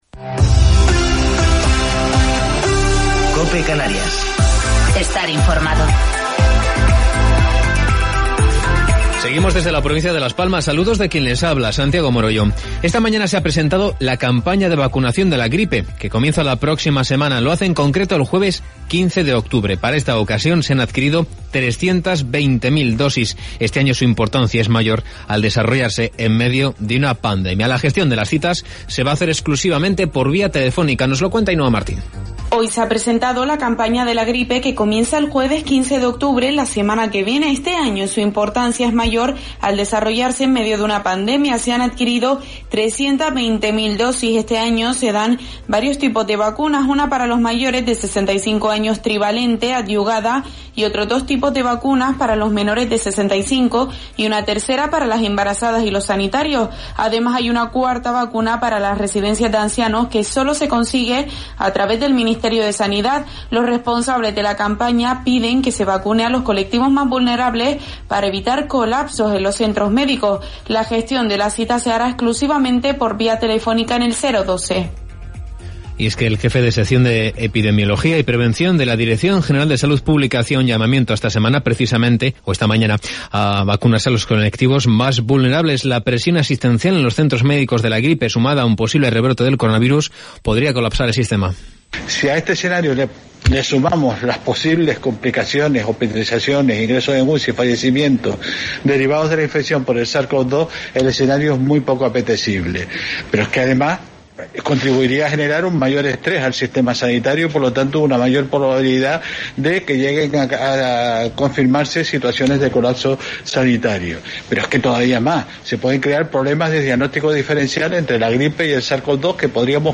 Informativo local 7 de Octubre del 2020